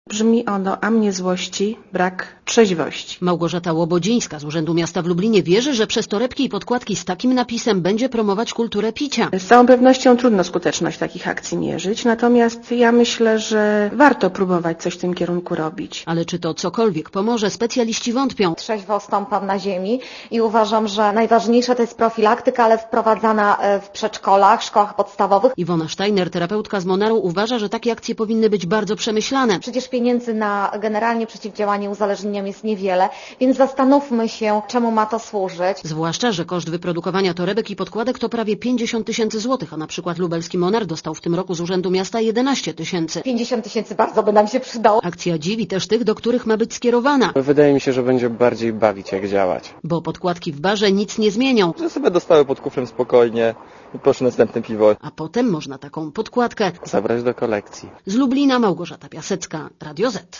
Posłuchaj relacji reporterki Radia Zet (235 KB)